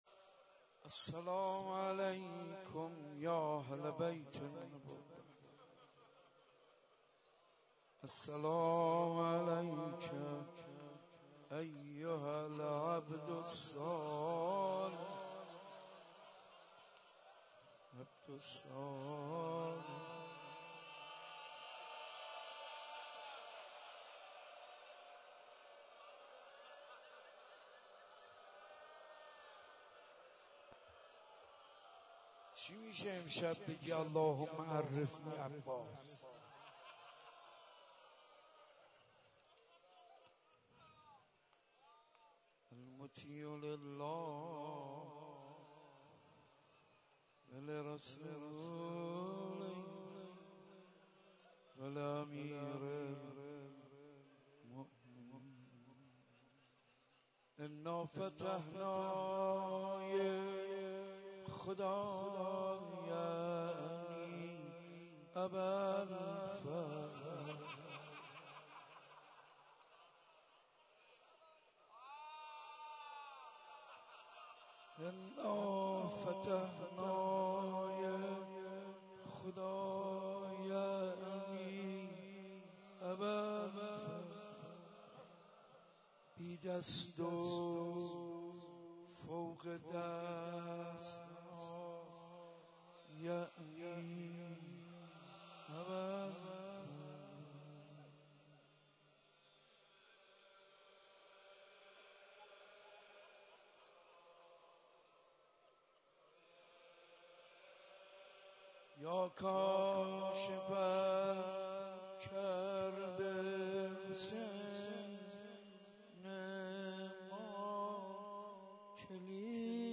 شب نهم محرم95/مسجد ارک تهران